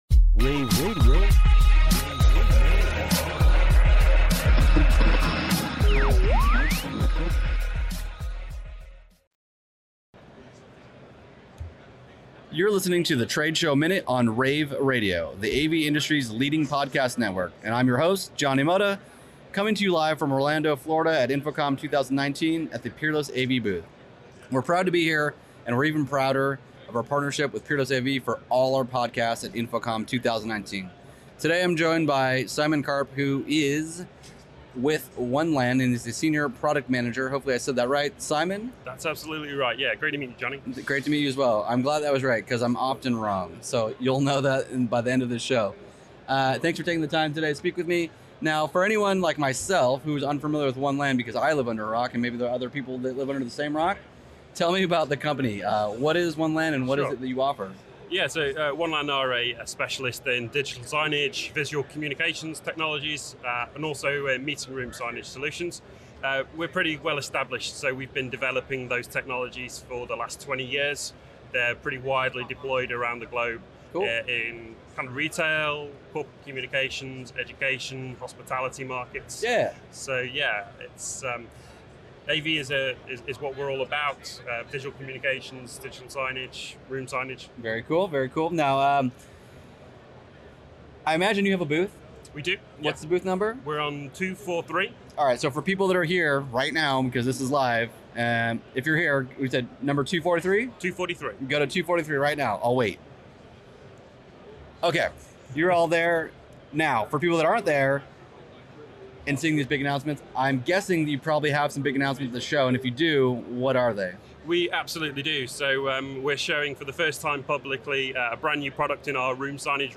Tagged InfoComm 2019